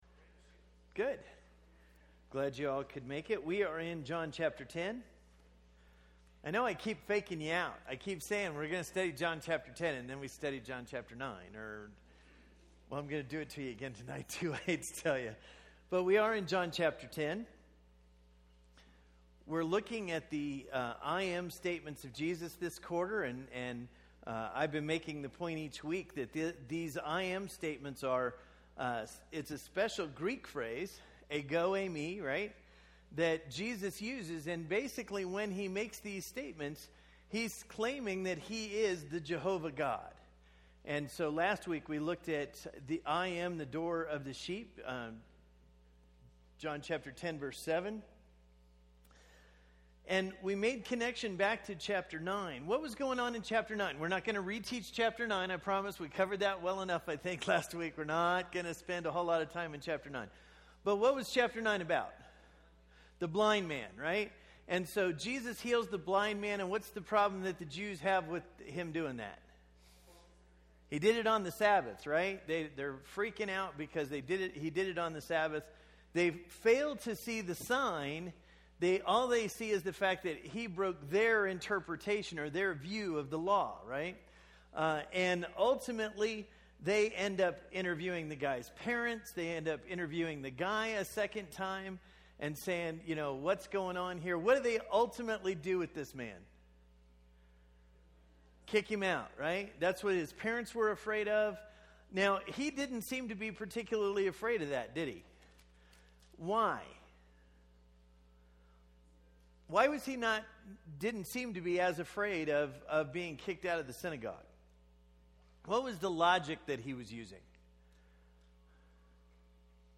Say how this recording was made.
This is a study of the I AM statements of Jesus in the Gospel of John. Tonight's lesson is from John 10, "I AM the good shepherd." These presentations are part of the Wednesday night adult Bible classes at the Bear Valley church of Christ.